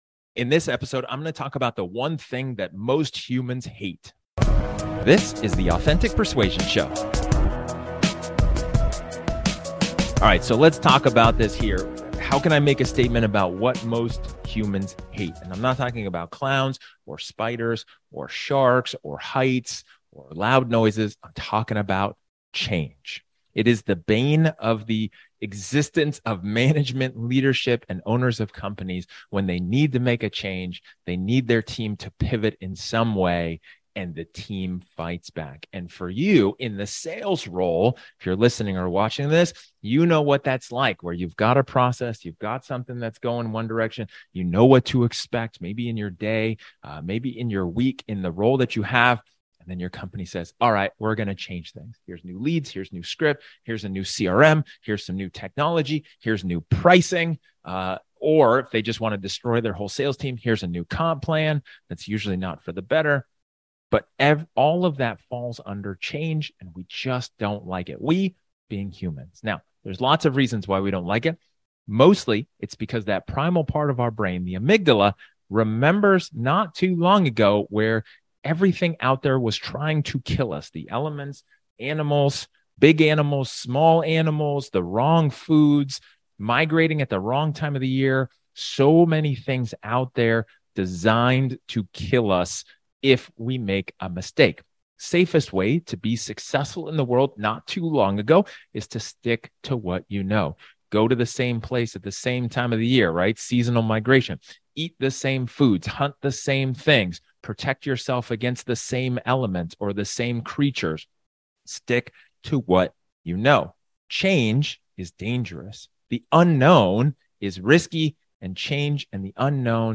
In this solo episode, I talk about the fear of change, and how should you manage to adapt to a change initiated by your sales leaders. For people in sales roles, you know what that's like when you are just so used to a process and then the company would direct you to some other way.